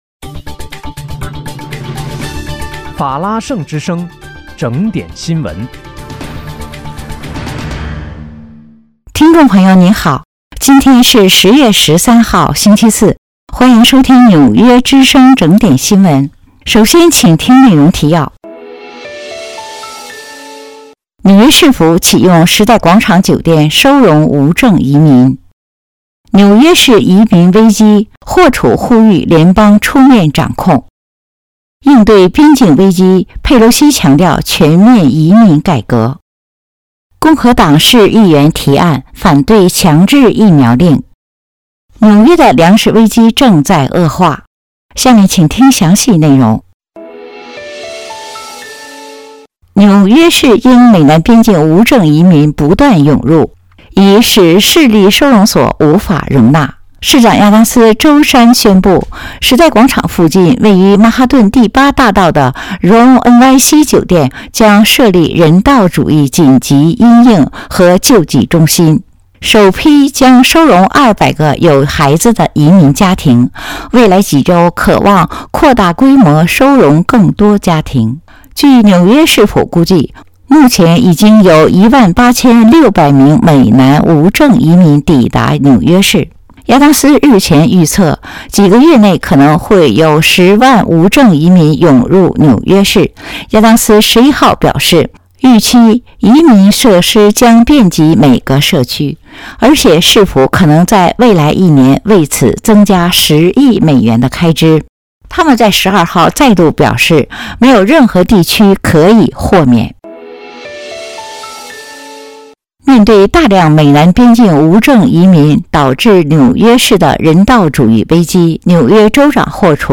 10月13号(星期四) 纽约整点新闻。